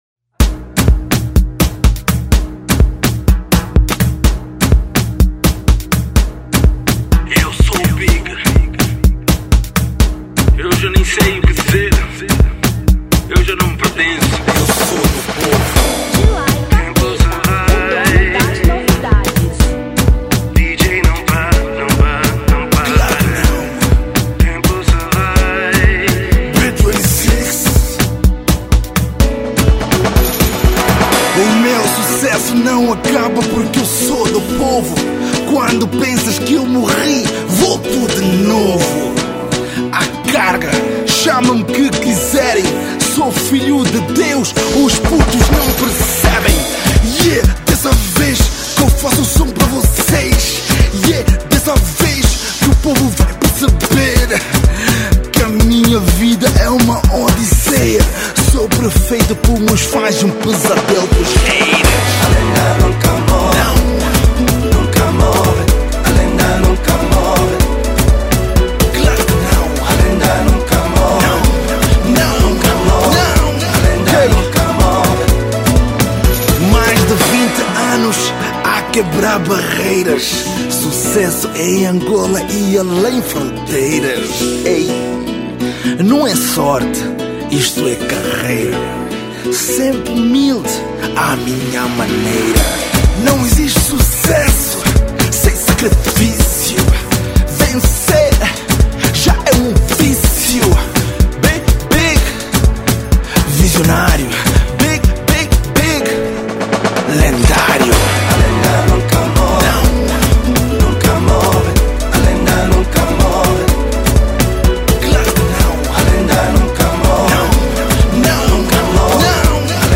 Afro House 2015